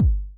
6kik.wav